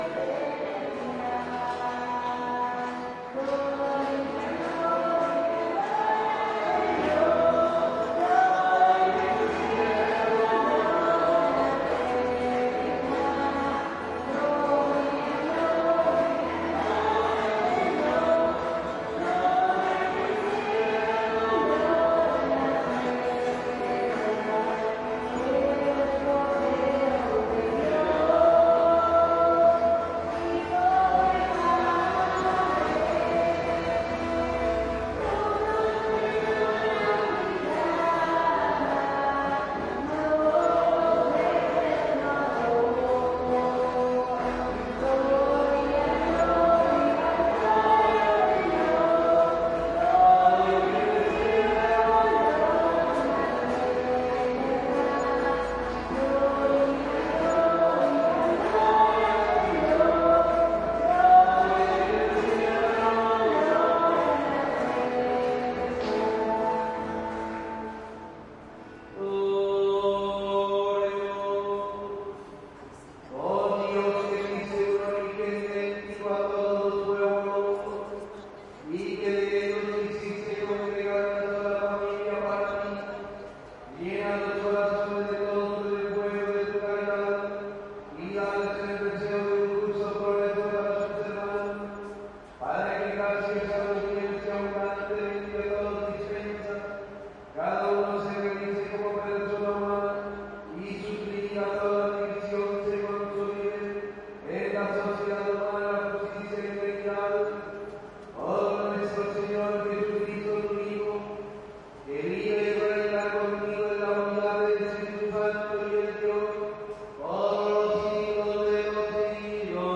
描述：天主教堂服务重型通风民间二重奏歌曲和牧师领导人群在祈祷中单调沉重的回声Saravena，哥伦比亚2016
Tag: 民谣 教堂 质量 牧师 祈祷 歌曲 服务 天主教